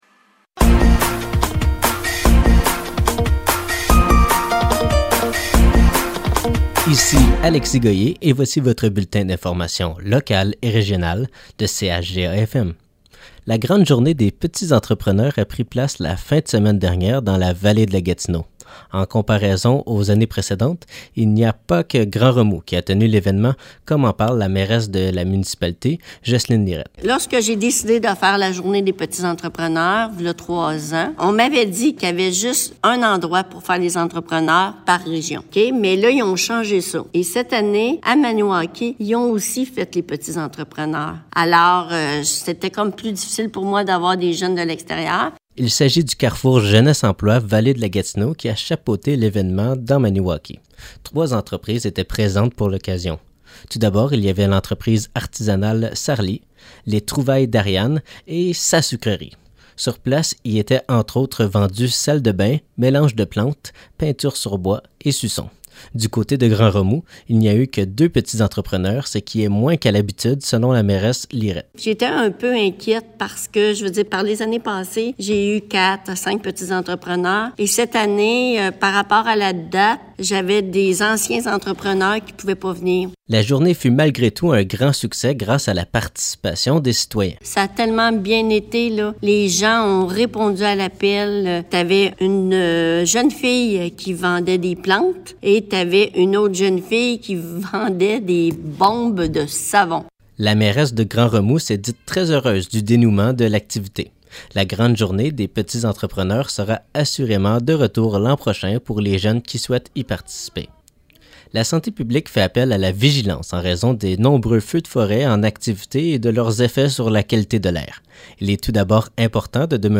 Nouvelles locales - 7 juin 2023 - 12 h